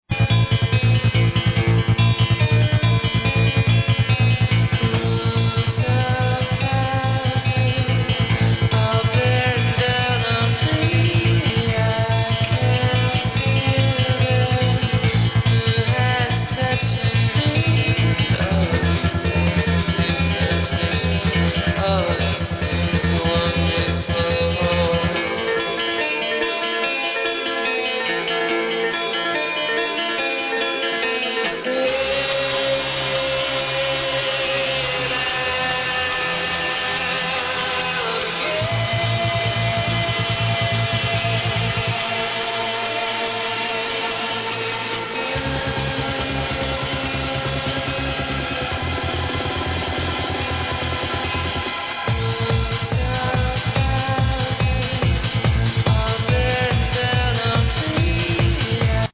Chill Vocal